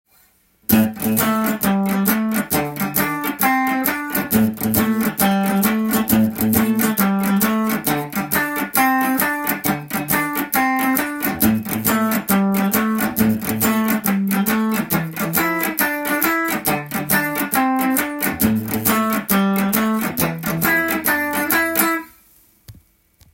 ジャキジャキさせながらブルースの伴奏をしています。
A Blues　カッティングtab譜
譜面通り弾いてみました
AのBluesの伴奏で使用できるカッティング使用の譜面です。
８分のハネの雰囲気を維持しながらメトロノームに合わせて